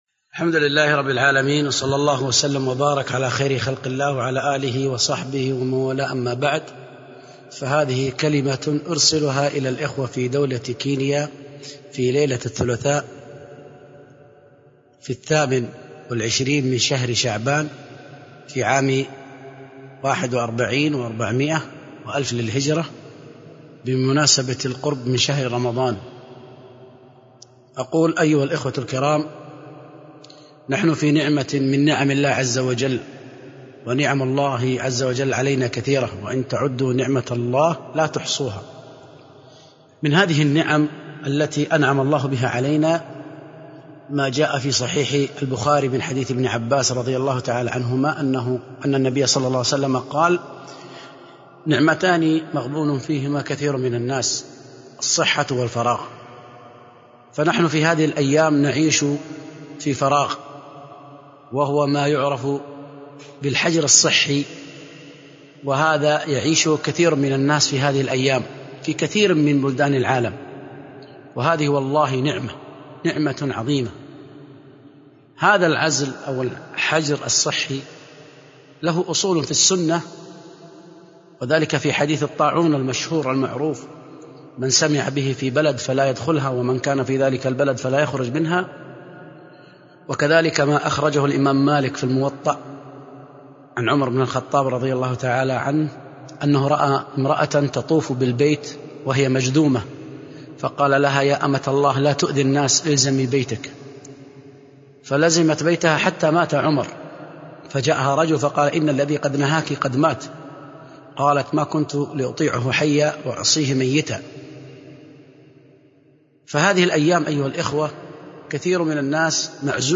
ألقيت في ليلة الثلاثاء 28شعبان1441هجرية
مكة المكرمة حرسها الله